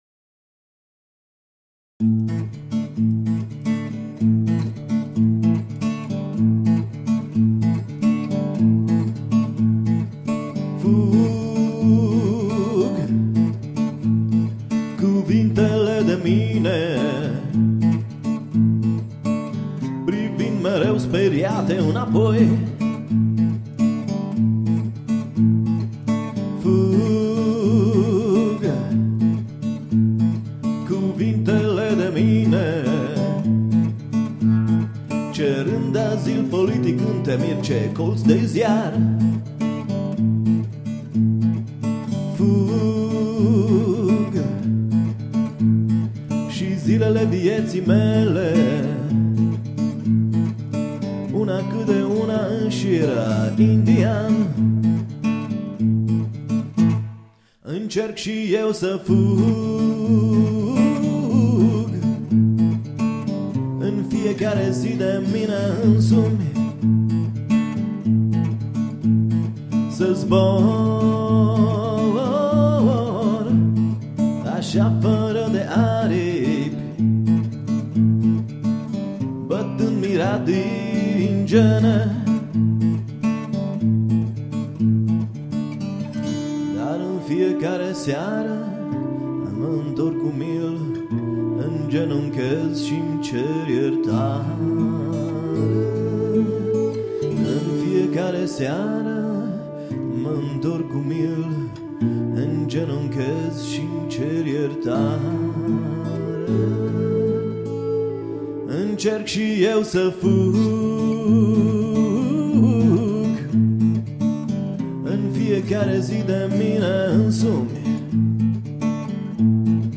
bazele unui duo folk & blues.